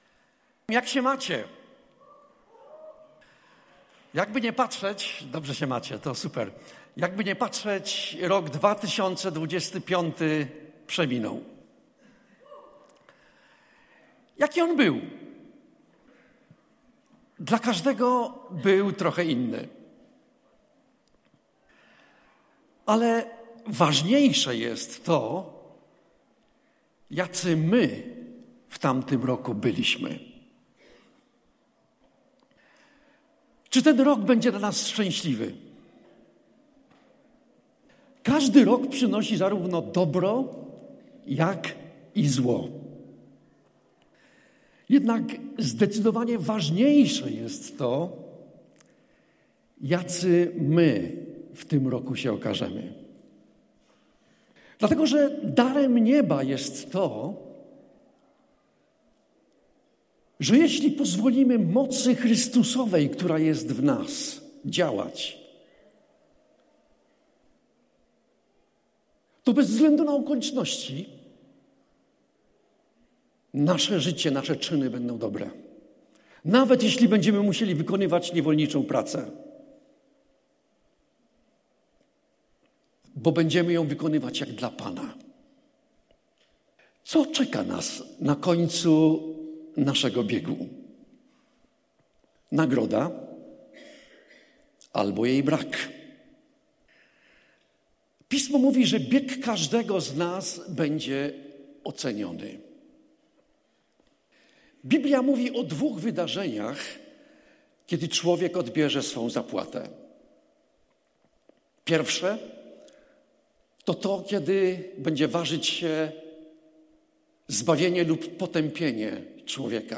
Pytania do przemyślenia po kazaniu: